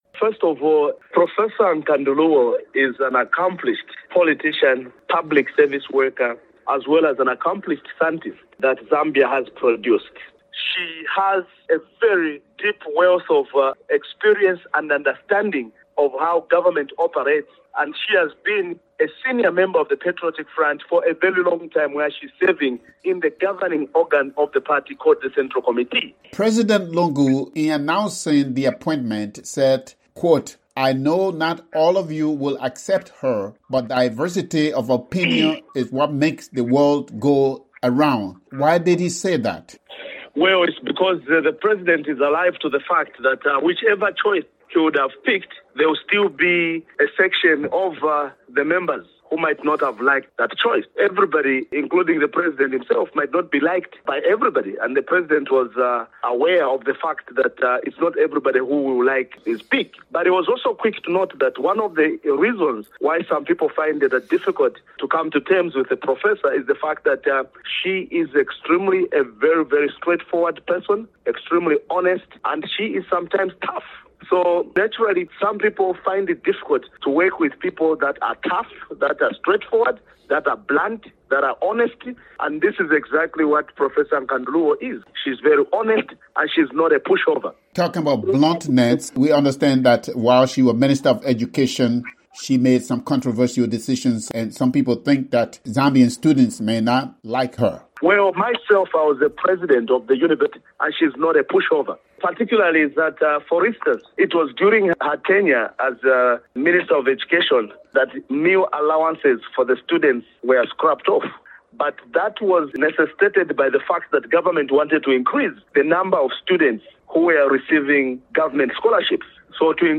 For Daybreak Africa